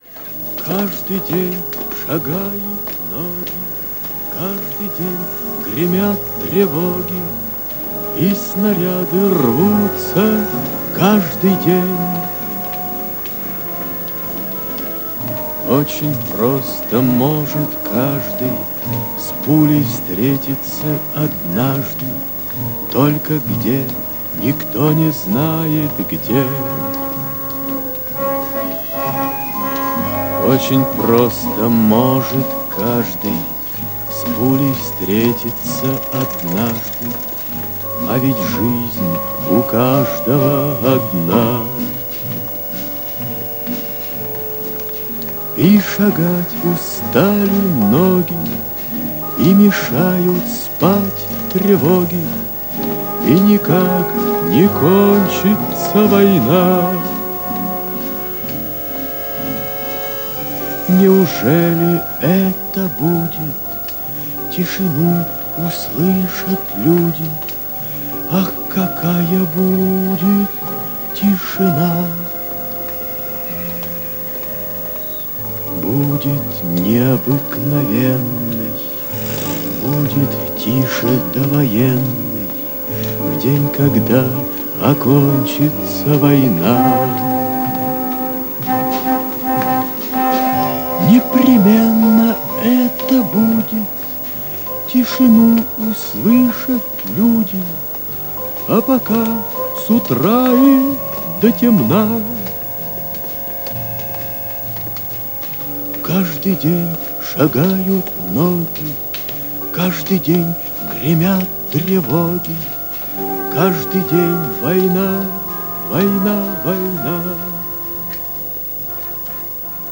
А в саундтреком для рассказа пусть станет песня А.Эшпая в исполнении И.Кобзона из к/ф "Майор Вихрь".